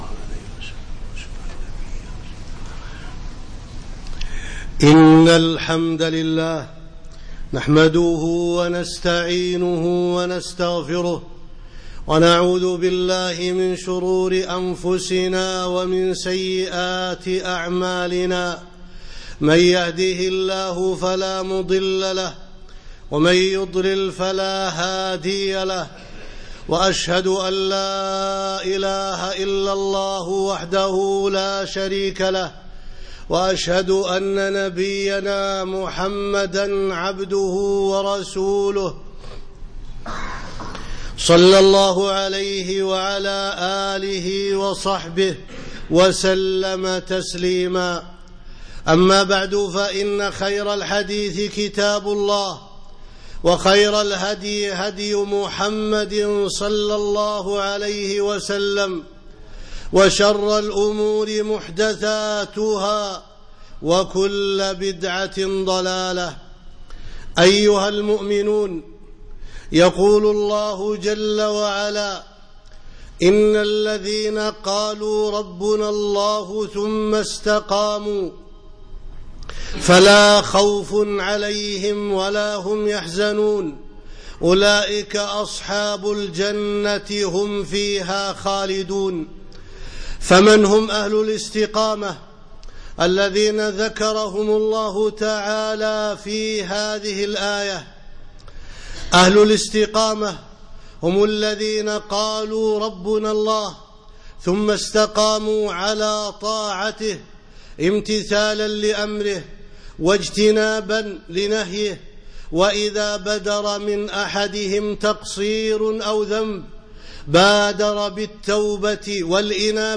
إن الذين قالوا ربنا الله ثم استقاموا - خطبة - دروس الكويت